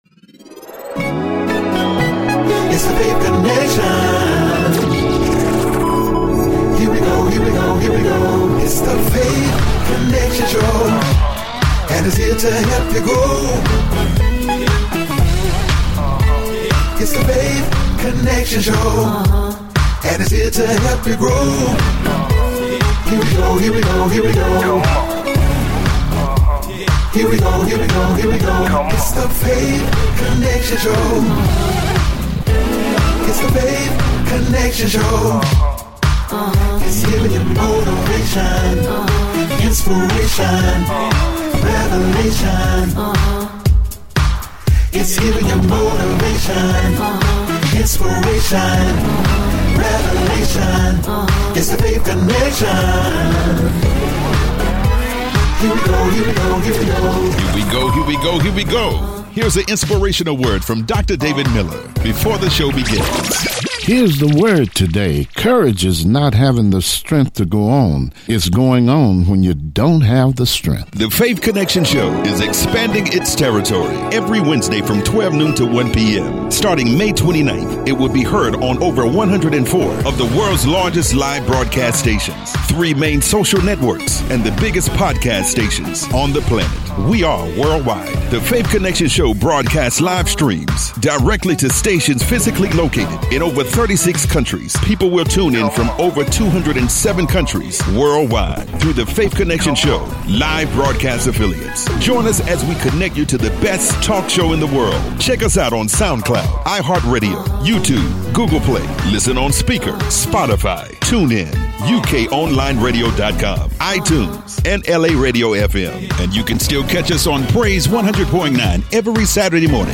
Weekly talk show in which we interview people that have gone through tremendous struggles, have overcome and been able to succeed.